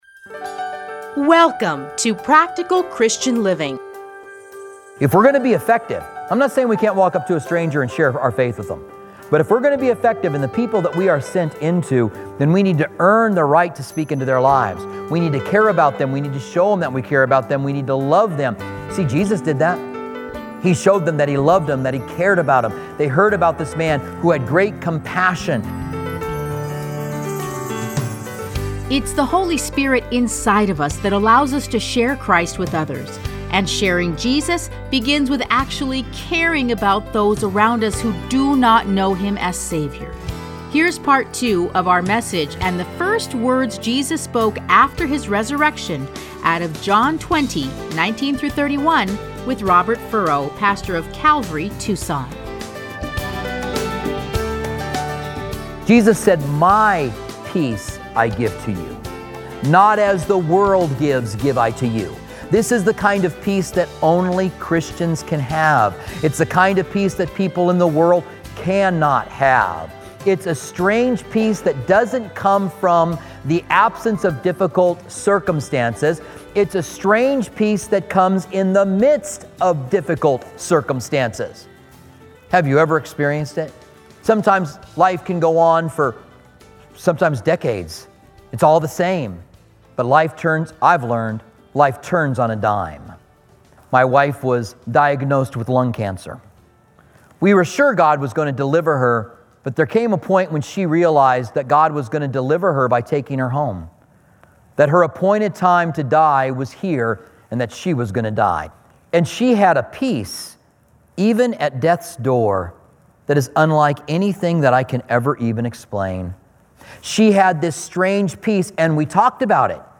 Listen to a teaching from John 20:19-31.